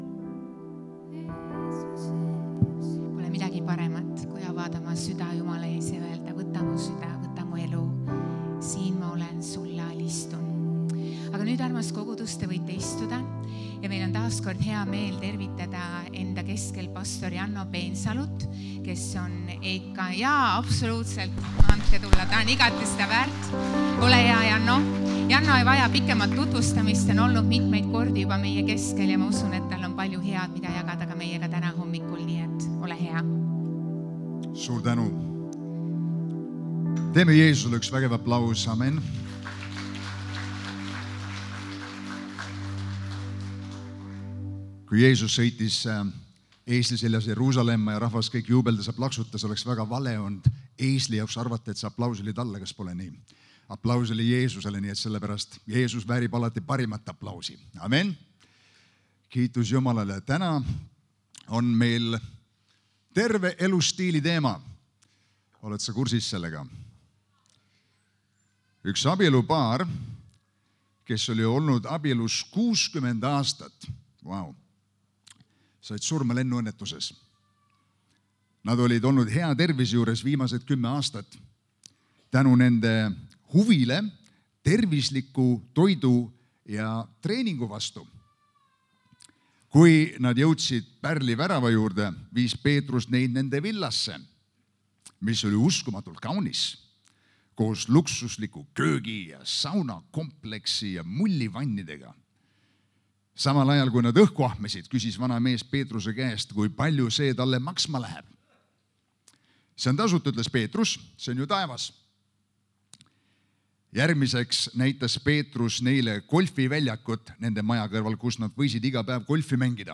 Jutlused - EKNK Toompea kogudus
Kristlik ja kaasaegne kogudus Tallinna kesklinnas.